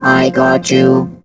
rick_kill_vo_03.wav